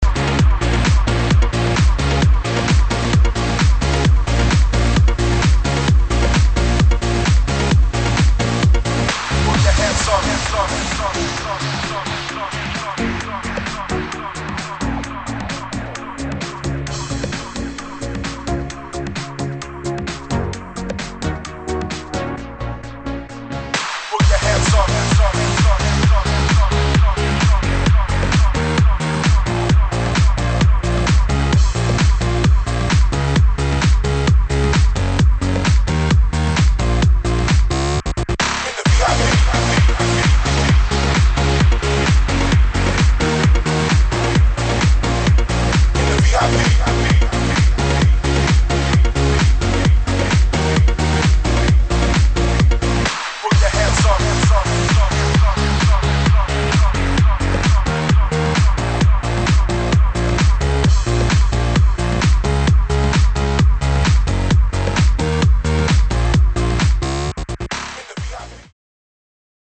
[ HOUSE | UKG | DUBSTEP ]